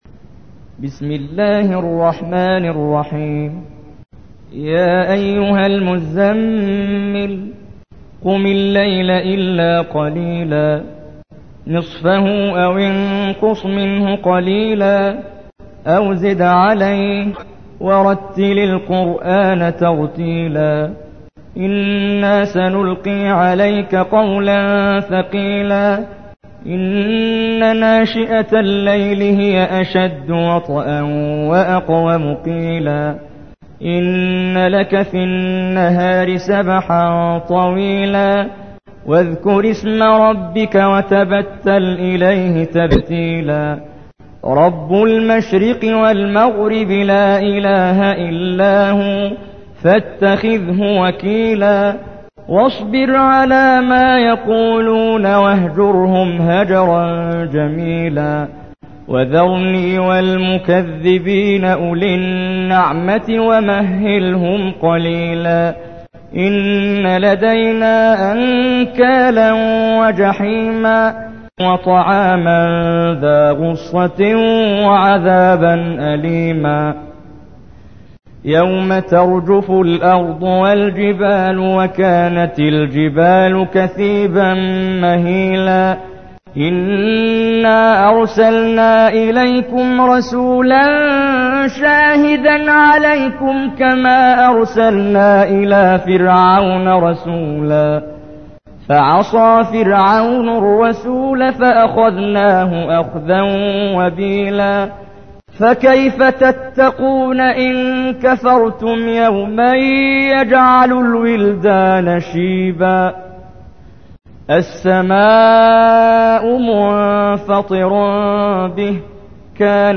تحميل : 73. سورة المزمل / القارئ محمد جبريل / القرآن الكريم / موقع يا حسين